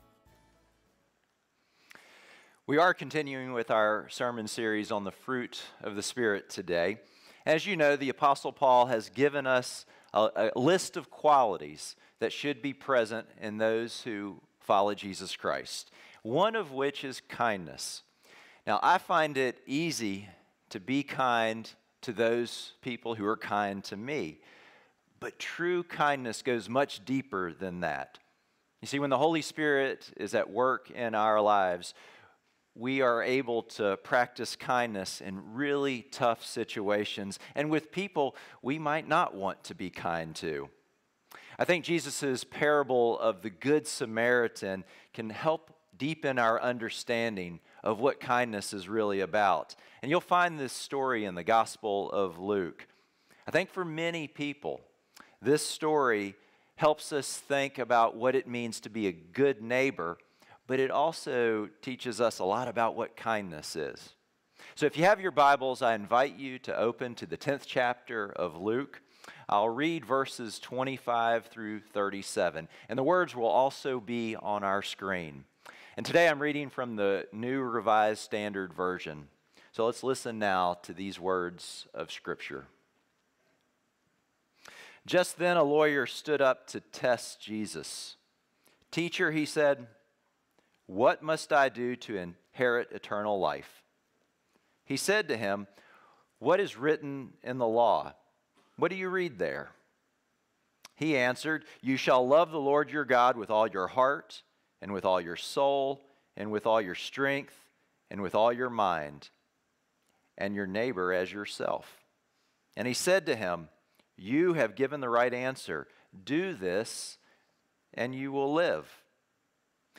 Sermons | Forest Hills Baptist Church